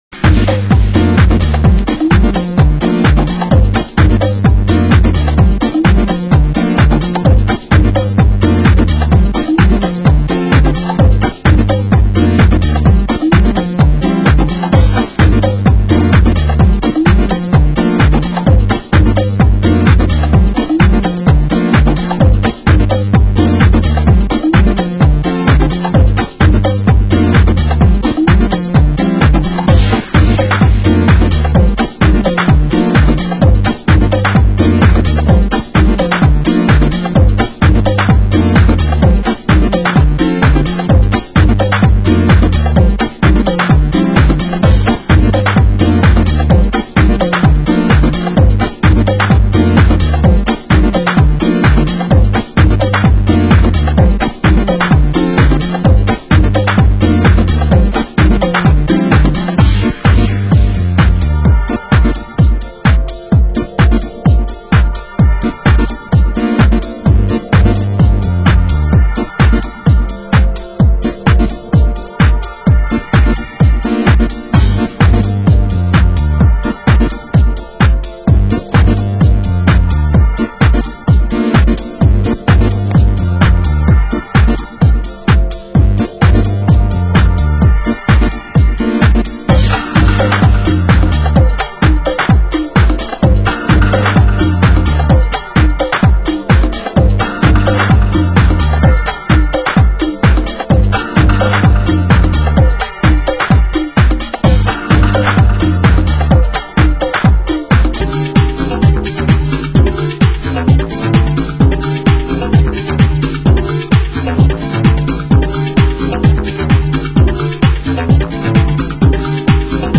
Genre Tech House